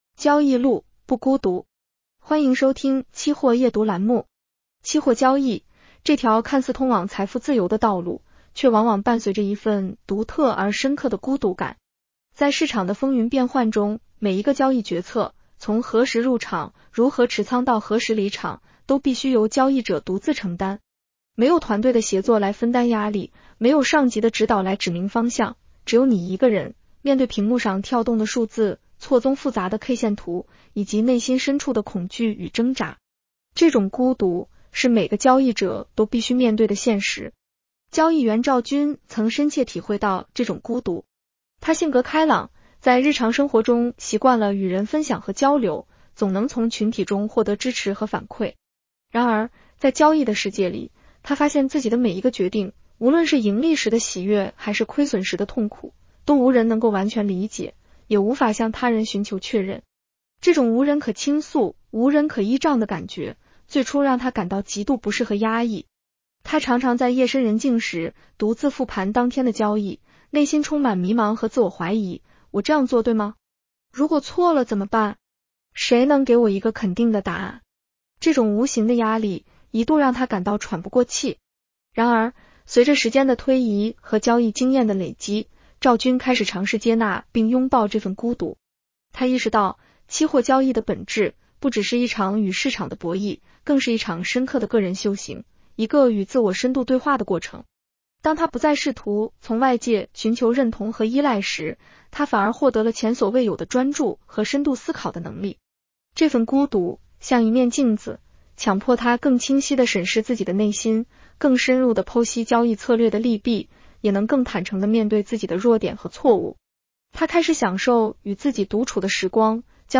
女声普通话版 下载mp3 交易路，不孤独。